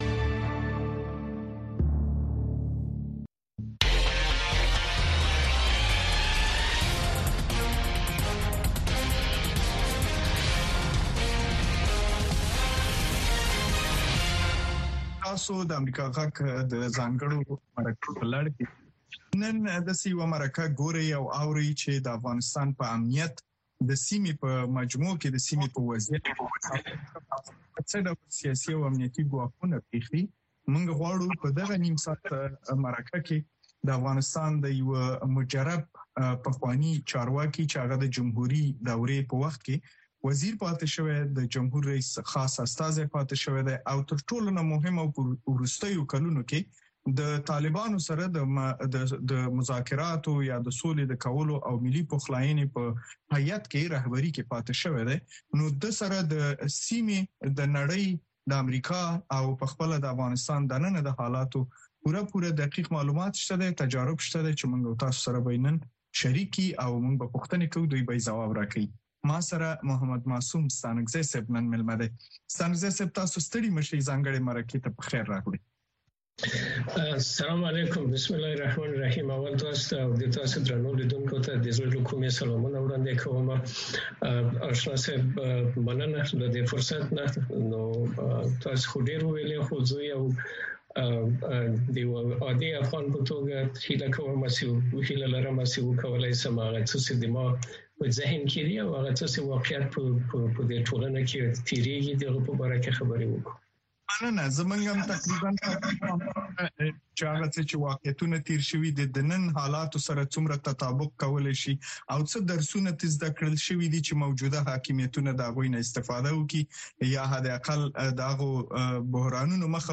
ځانګړې مرکه